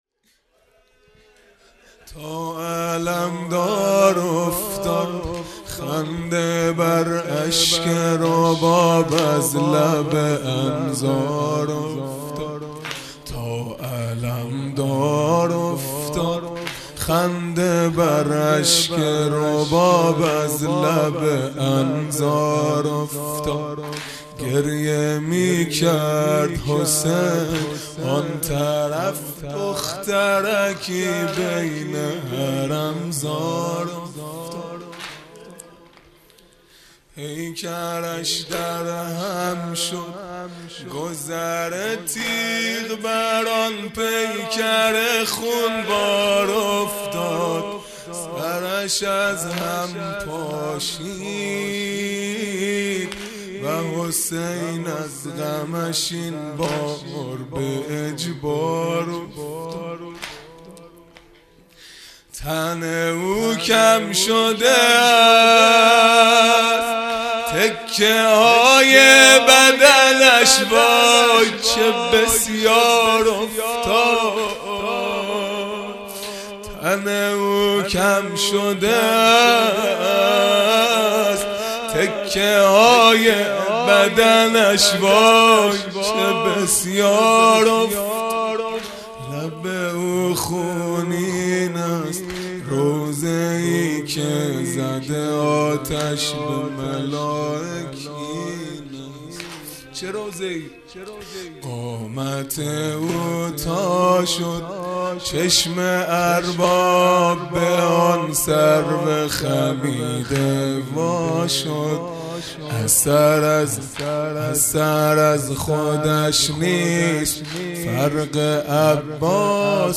خیمه گاه - هیئت بچه های فاطمه (س) - واحد دوم | تا علمدار افتاد
دهه اول محرم الحرام ۱۴۴٢ | صبح تاسوعا